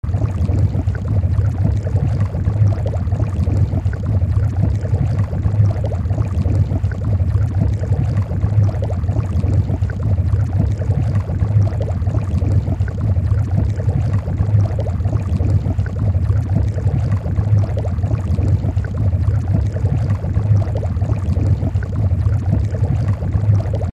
Звук лопающихся пузырьков воды в лабораторных условиях